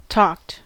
Ääntäminen
Ääntäminen US Tuntematon aksentti: IPA : /tɔːkt/ Haettu sana löytyi näillä lähdekielillä: englanti Talked on sanan talk partisiipin perfekti.